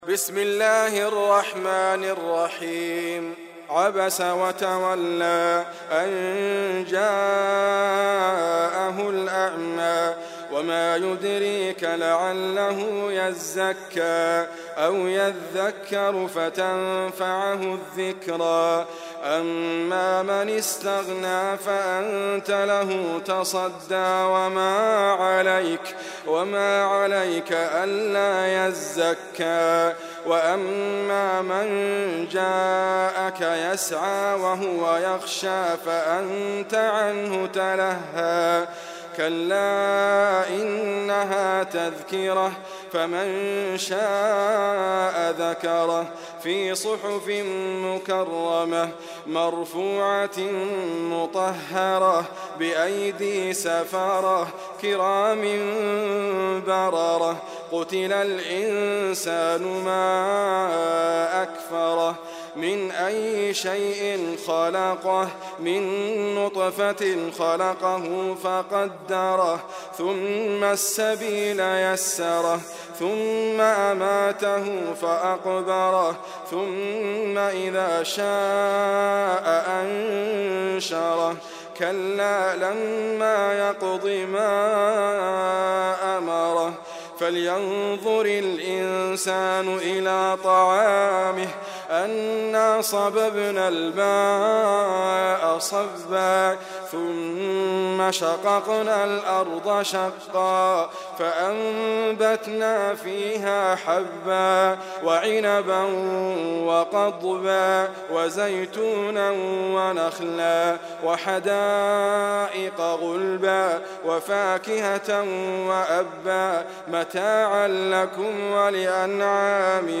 القران الكريم -> ادريس ابكر -> عبس